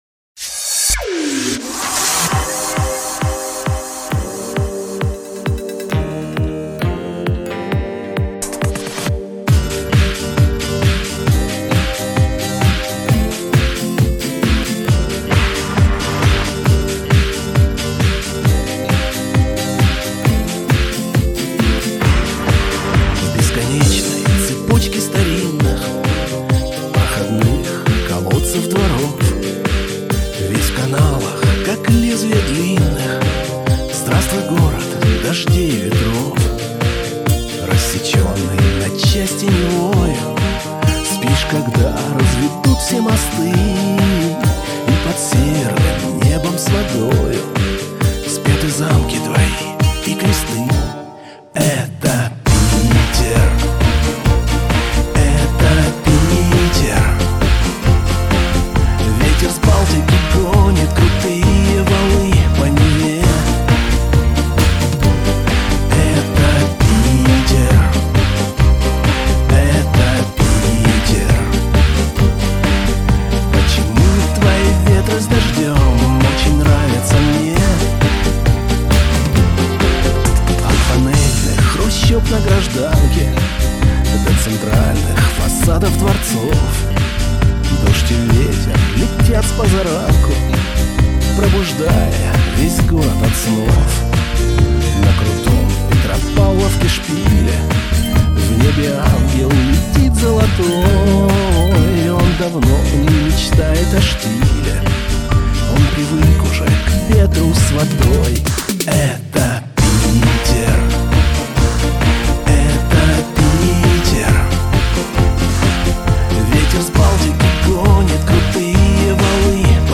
drum&bass version